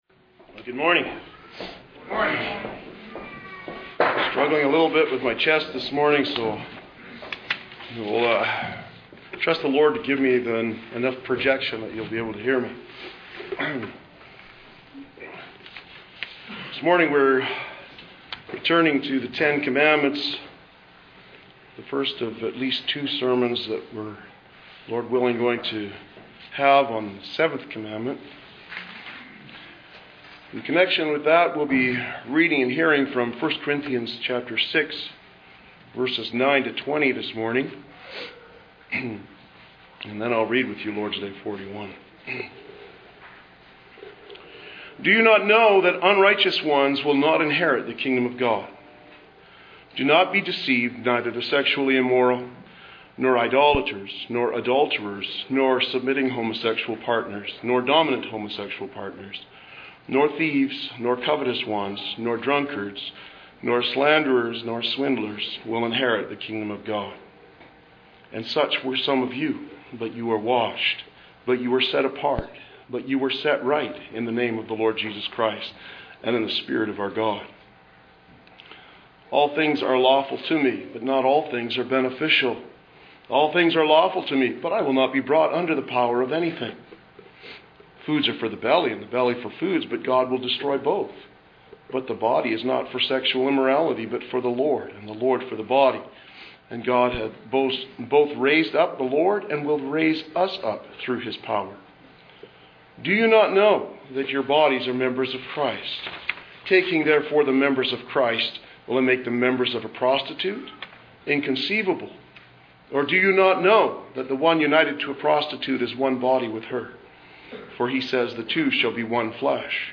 Three Sermons on Purity (Seventh Commandment)